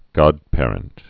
(gŏdpârənt, -păr-)